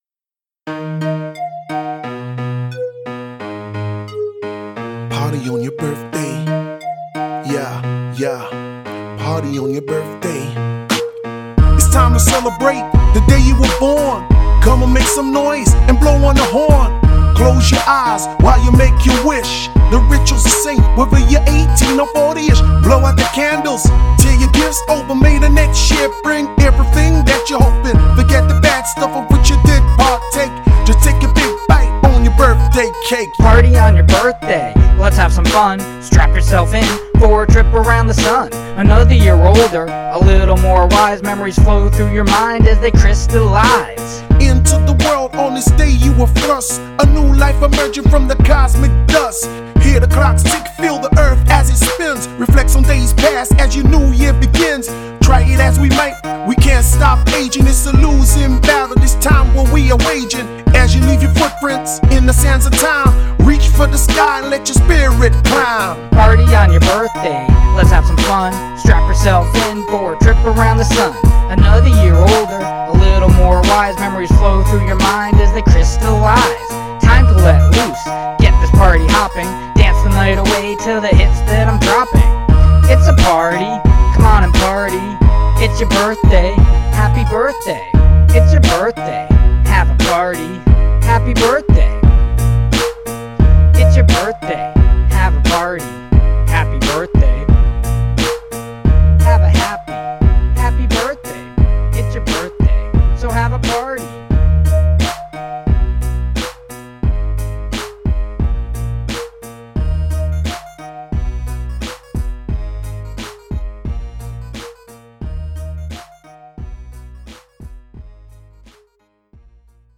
• Great song, great beat, nice energetic tempo.
• Starts out like a children's song but picks up nicely.
The music is low fi and simple. The vocals are pretty retro.